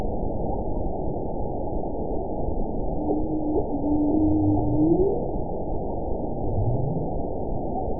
event 921829 date 12/19/24 time 08:27:53 GMT (10 months ago) score 9.40 location TSS-AB02 detected by nrw target species NRW annotations +NRW Spectrogram: Frequency (kHz) vs. Time (s) audio not available .wav